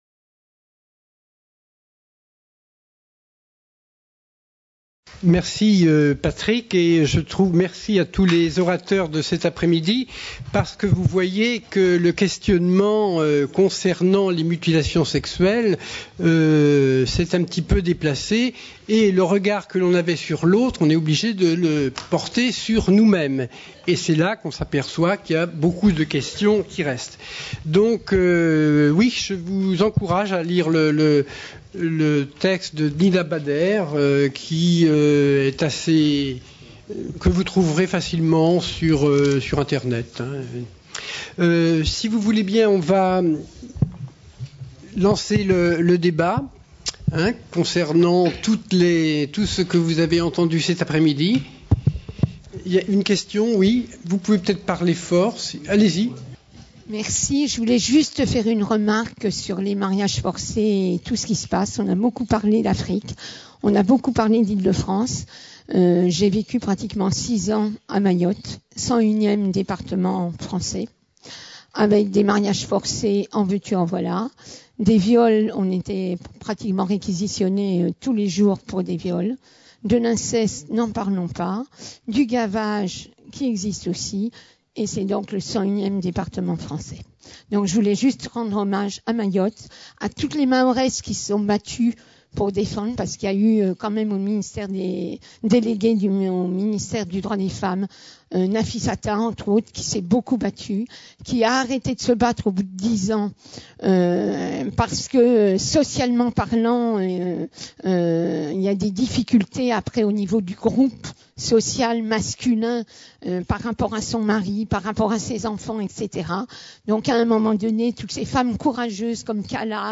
4ème Journée Humanitaire sur la Santé des Femmes, organisée par Gynécologie Sans Frontières, le 29 novembre 2013, au Palais du Luxembourg (Paris). De la culture traditionnelle à la mode, quand la chirurgie devient une autre forme de violence faite aux femmes.